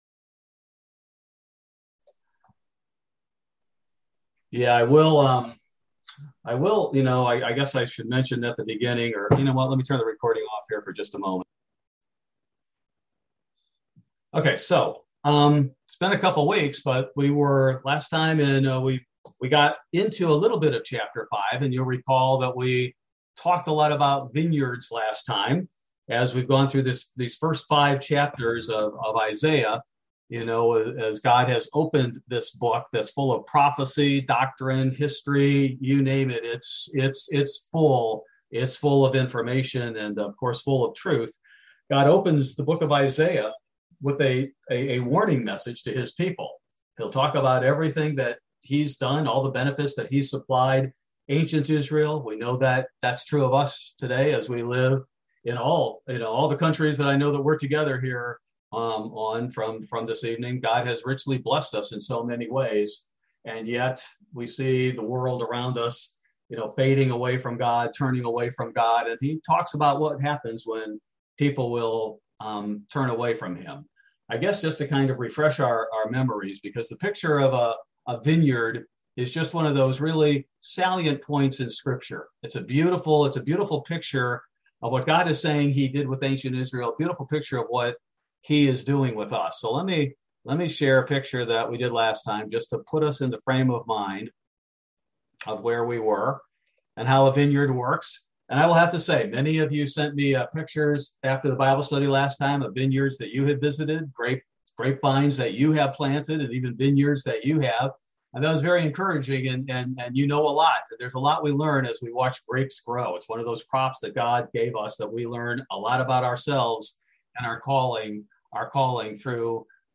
Bible Study: July 27, 2022